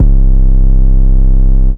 808 (CAN´T SAY C).wav